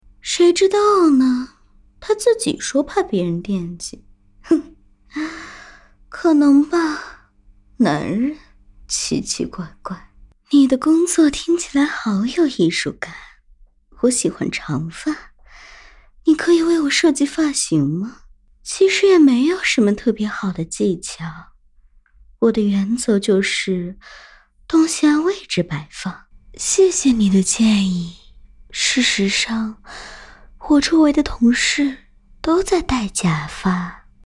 Onee-san_Sourse.MP3